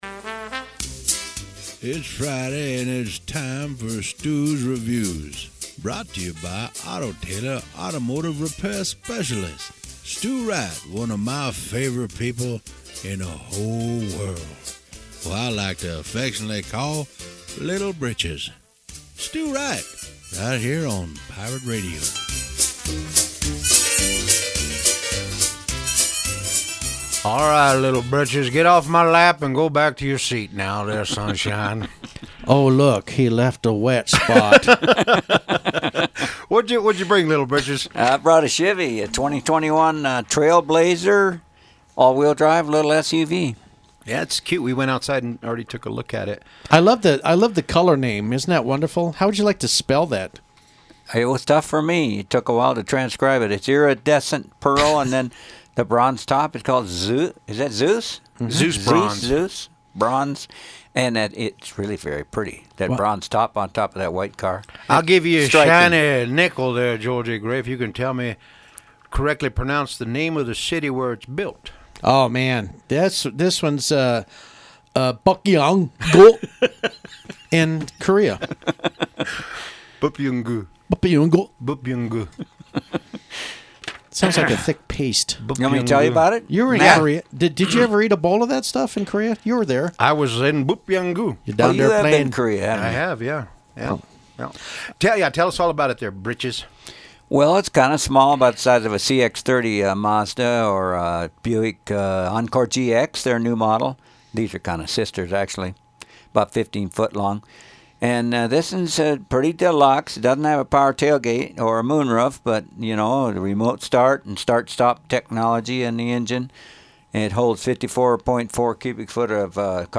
Radio review at Pirate 104.7 studios